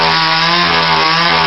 SawHit.ogg